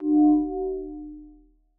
movement.wav